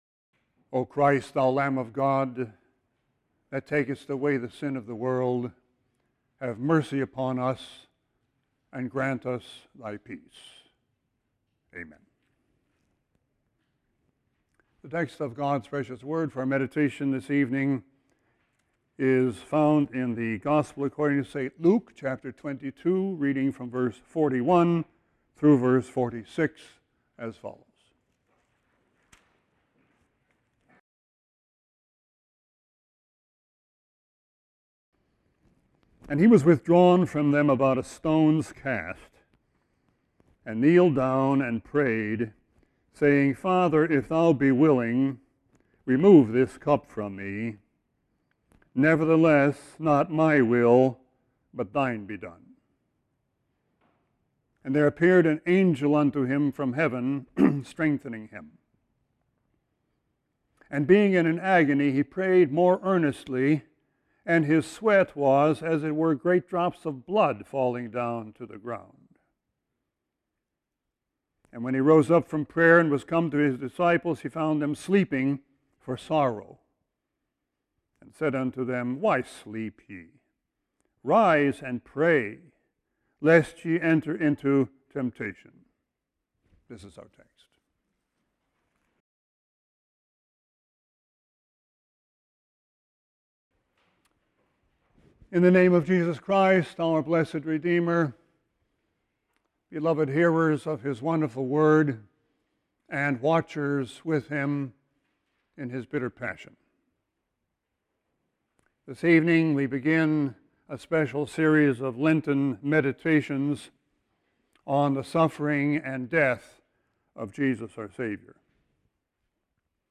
Sermon 2-26-20.mp3